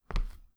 hard-footstep1.wav